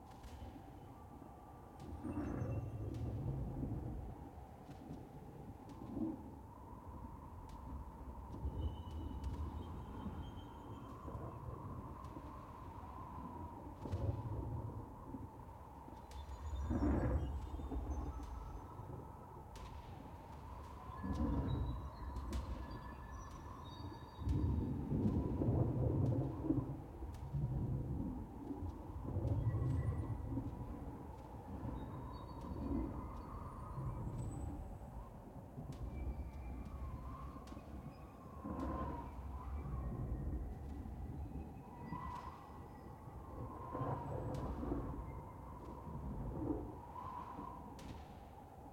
sfx-pm-hub-amb-01.ogg